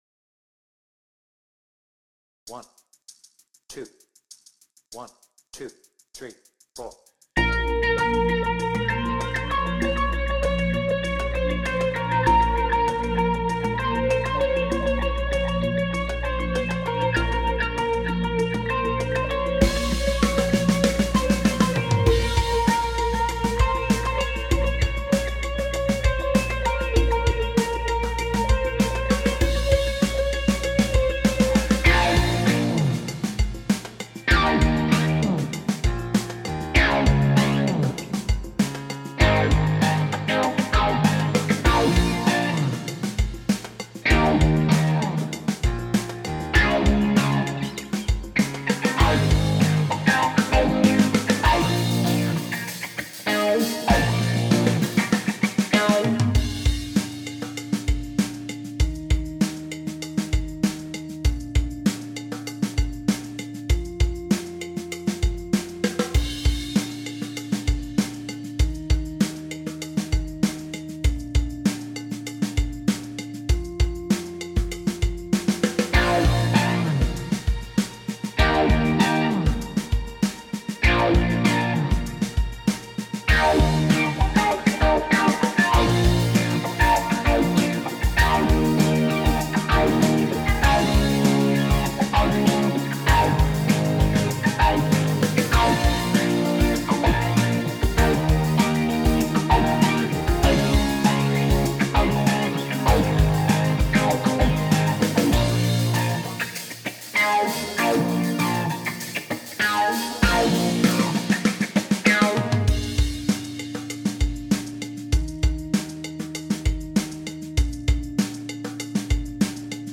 BPM : 98
Without vocals